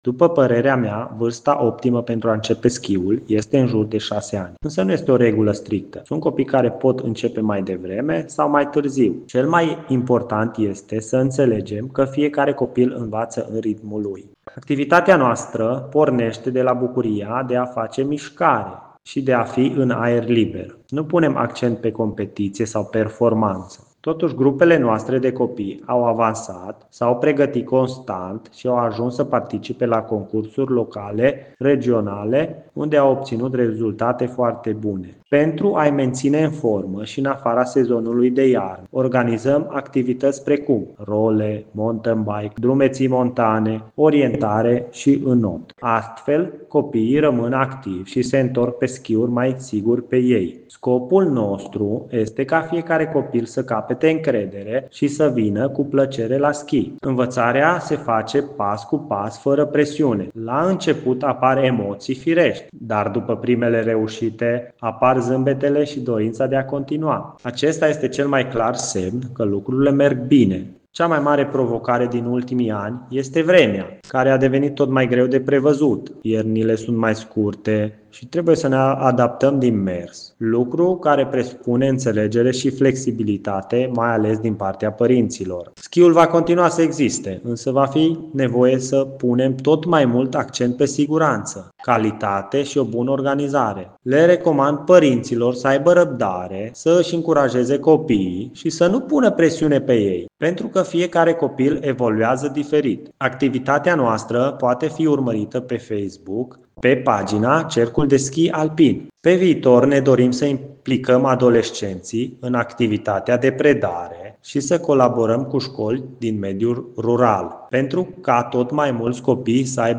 voce-antrenor-schi.mp3